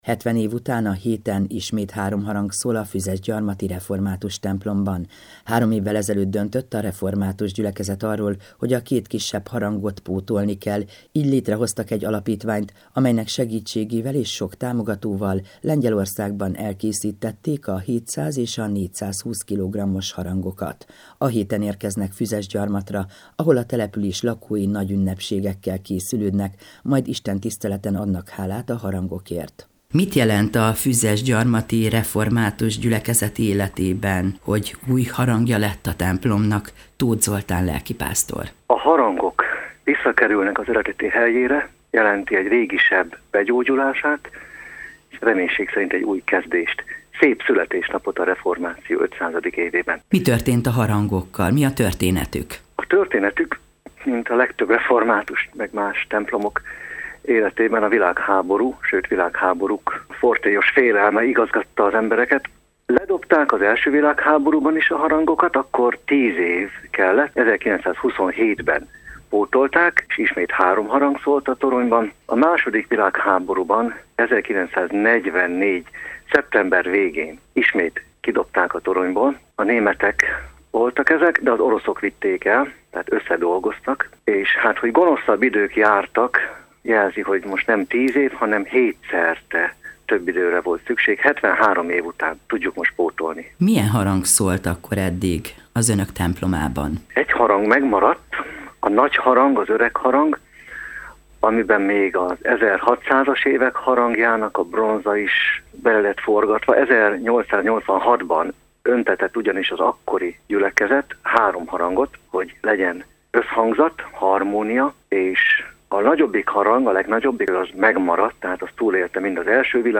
Hálaadó istentisztelet a Füzesgyarmati Református Egyházközségben - hanganyaggal
A hálaadó istentiszteleten dr. Fekete Károly tiszántúli püspök hirdette az igét.
16-40-fuzesgyarmat-harang.mp3